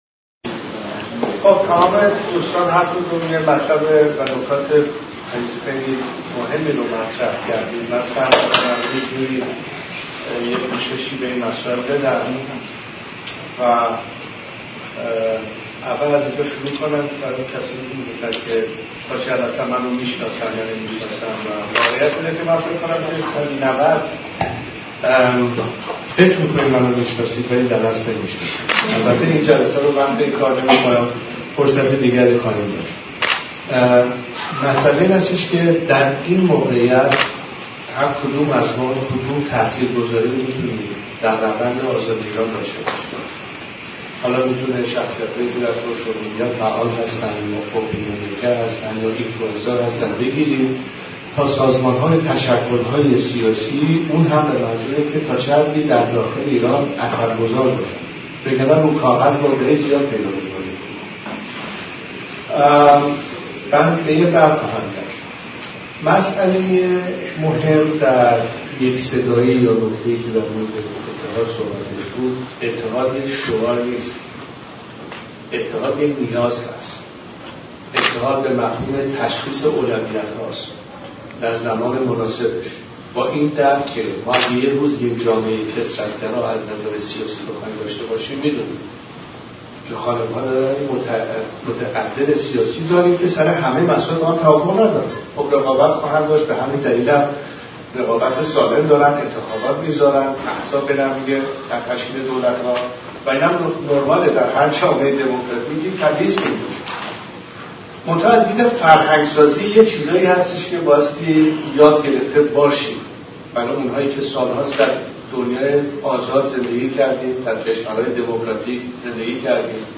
رضا شاه دوم در دیدار با ایرانیان لُس آنجلس
پادکست گلچین سخنان رضاشاه دوم در دیدار اخیرشان با ایرانیان لس آنجلس